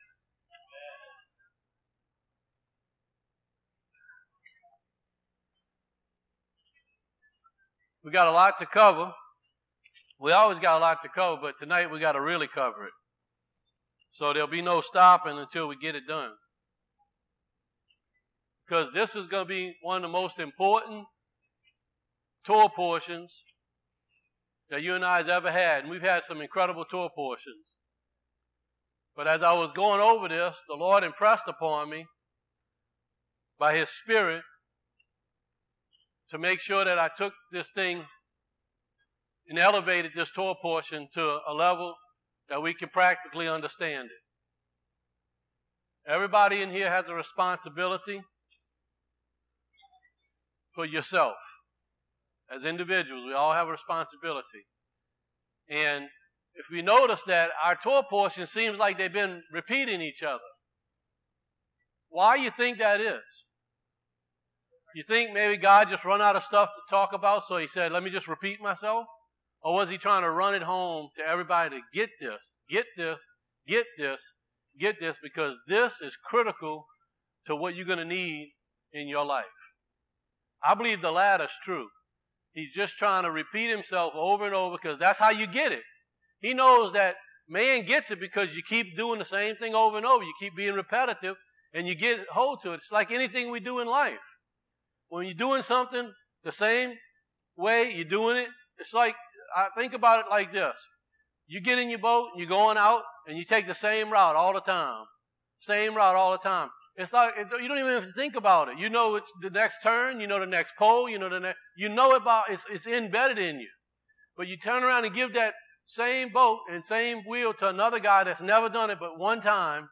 Torah Teachings – Pekudei Part 1